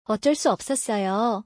オッチョ ス オソッソヨ